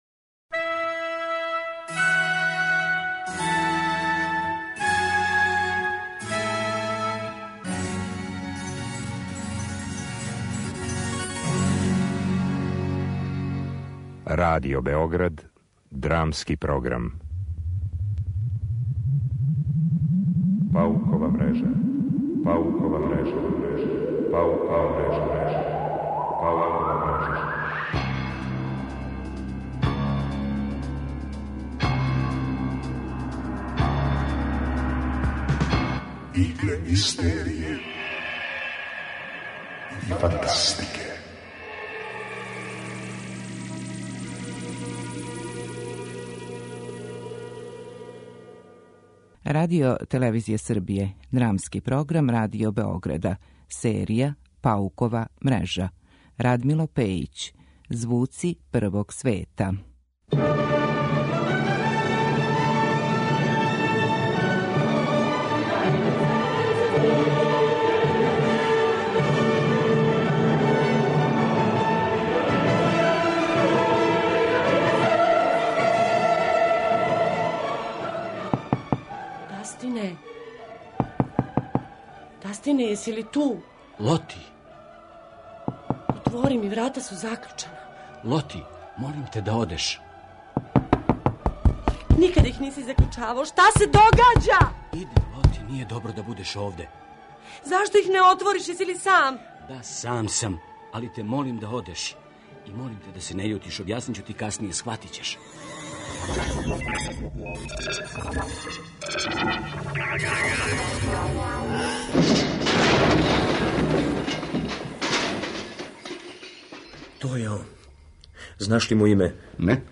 Драмски програм: Паукова мрежа